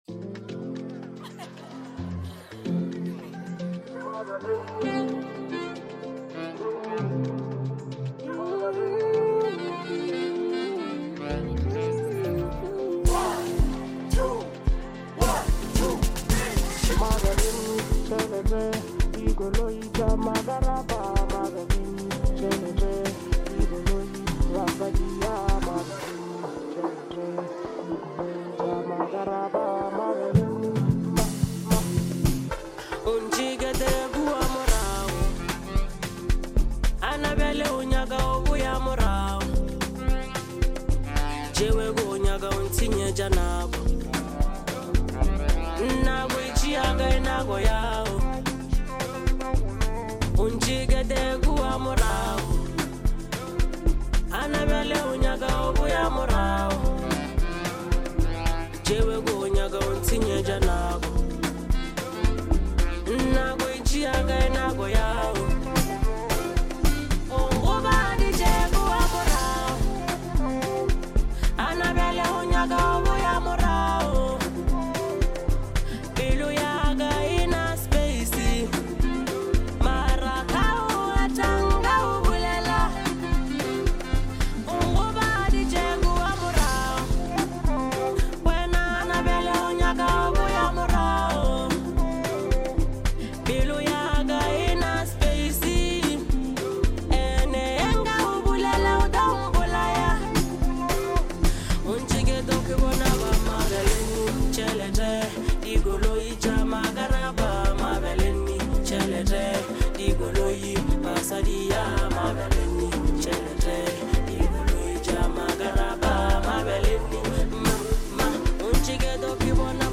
There is undeniable energy